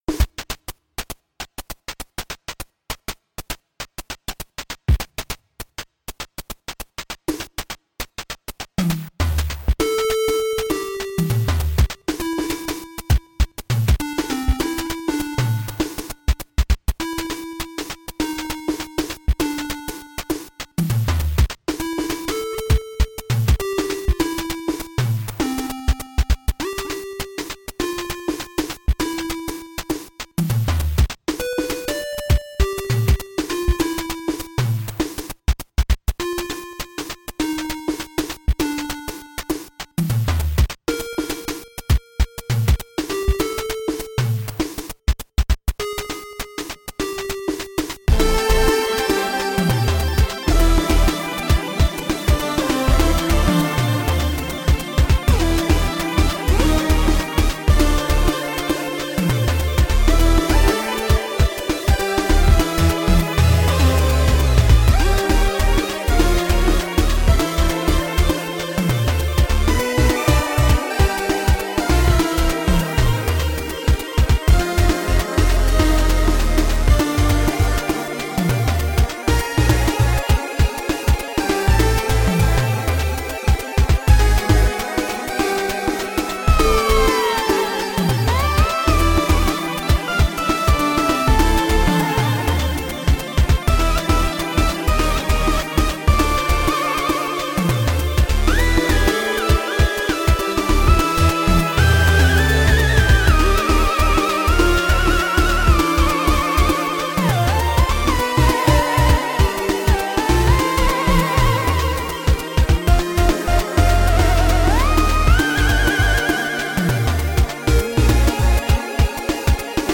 with guitar and heavy post-production
The chords were especially pretty.